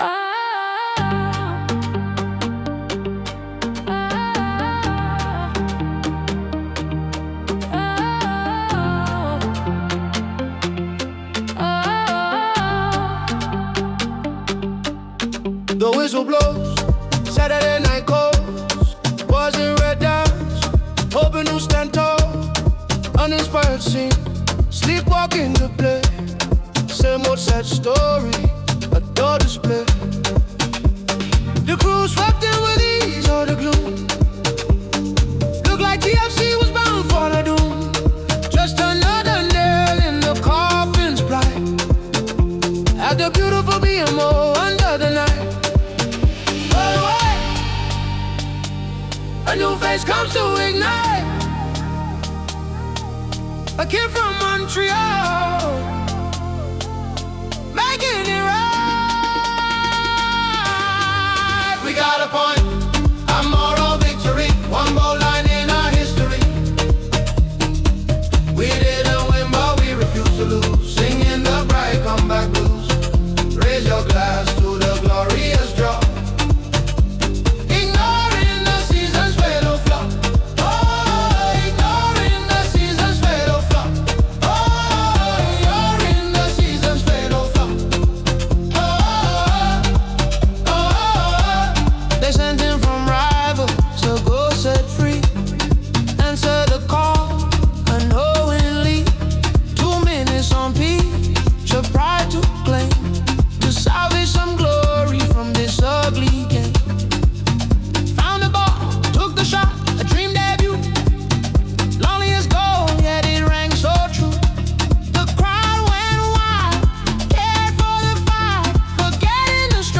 La canción que nace del dolor y lo vuelve íntimo: un Afrobeat house, samba reggae donde la tristeza del empate tardío se transforma en una confesión nocturna. Mucho espacio, reverb en las palmas y unos tambores y guitarras que acompaña la voz. Ideal para escuchar con auriculares después del partido, cuando el estadio ya está vacío y las sensaciones quedan a solas.